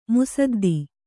♪ musaddi